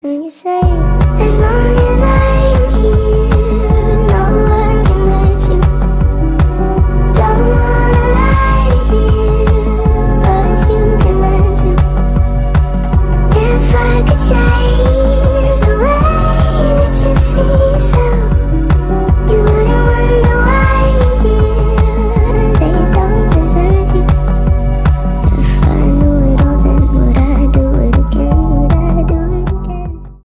Ремикс
тихие